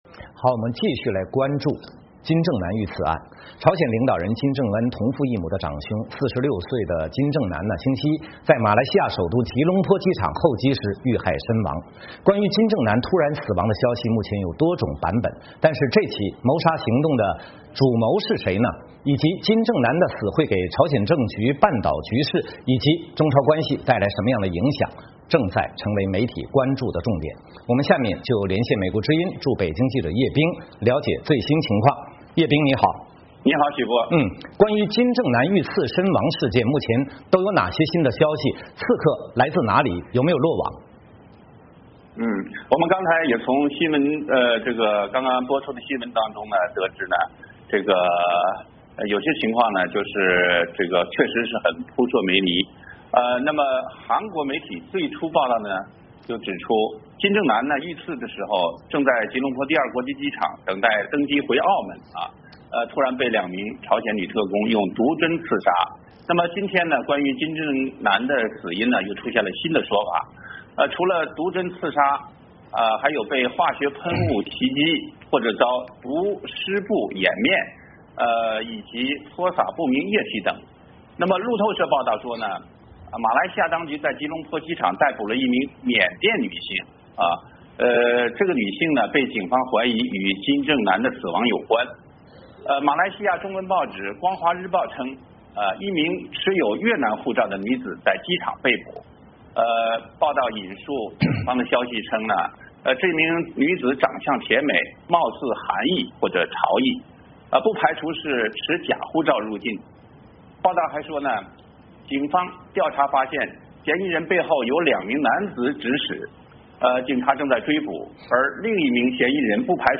VOA连线：金正男之死及其影响